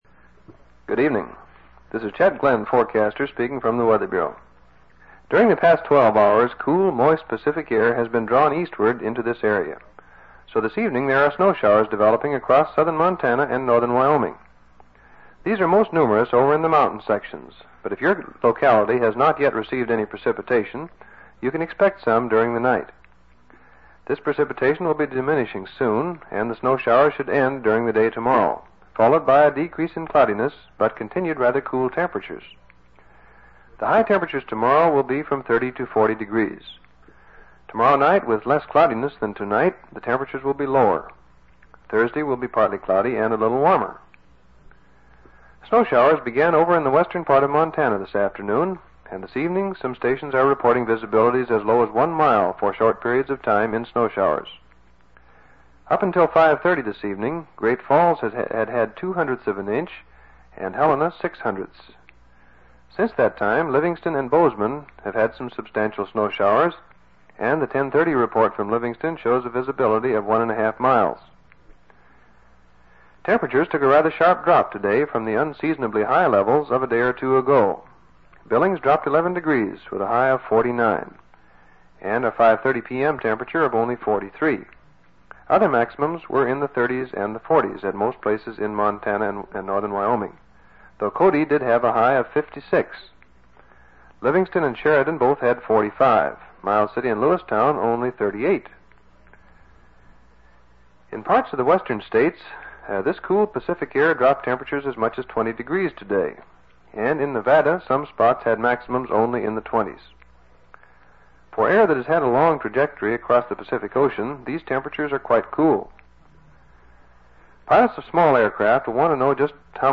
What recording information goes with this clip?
Weather Report x/x/x 3:08 From the airport weather service